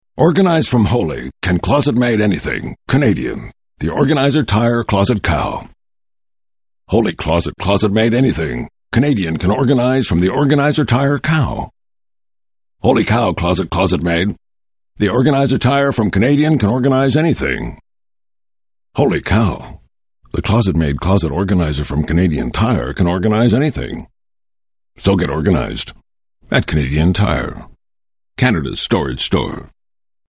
CT - Holy Cow - Radio Spot